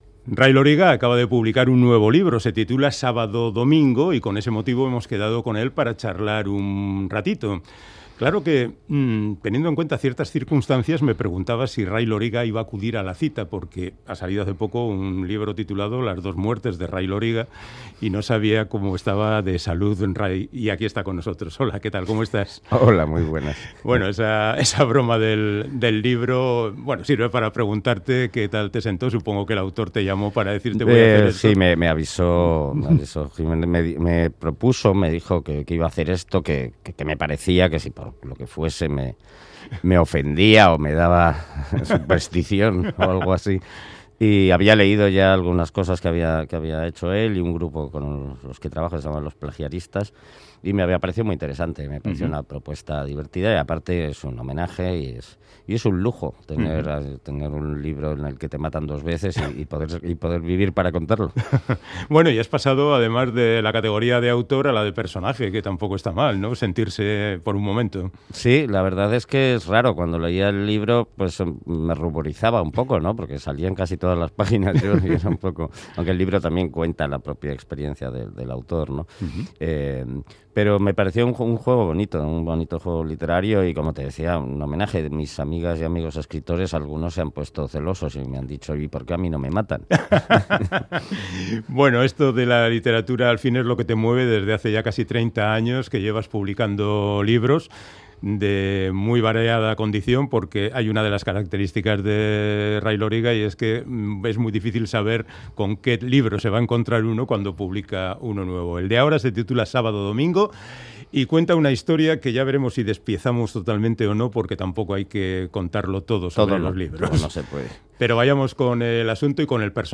Audio: Charlamos con el madrileño Ray Loriga sobre su nueva novela, "Sábado, domingo", en la que reflexiona sobre los pecados del pasado y la justicia sin cumplir